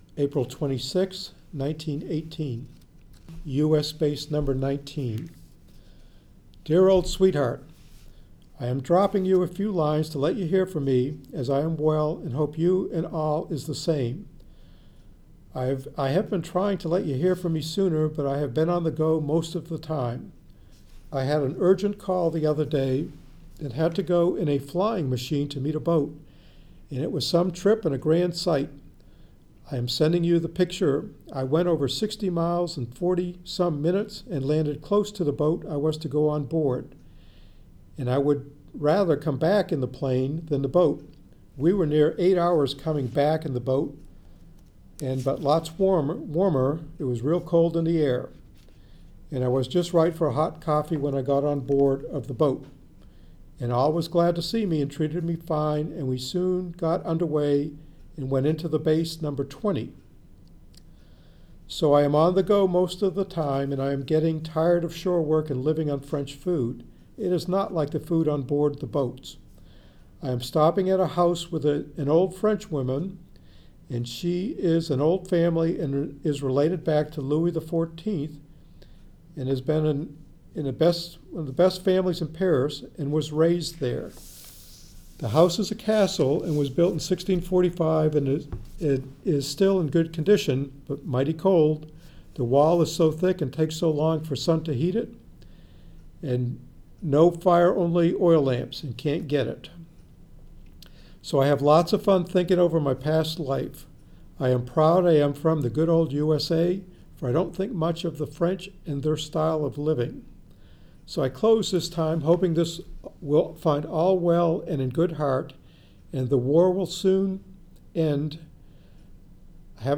We are truly honored to have local U.S. military veterans from different backgrounds read portions of the Letters Home Collection from The Mariners’ Museum Library.